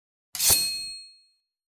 SwordSoundPack
SWORD_07.wav